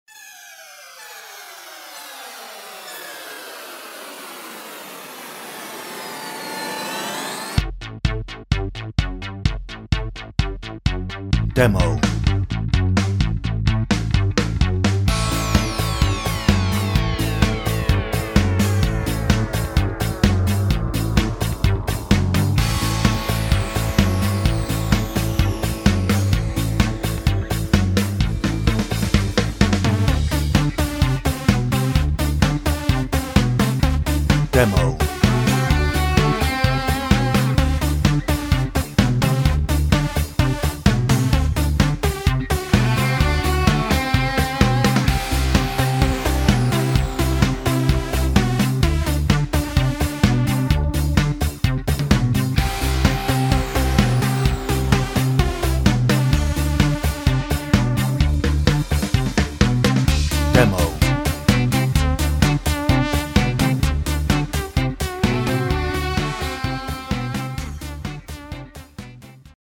Description Reviews (0) Hoedown - No ref vocal.
Instrumental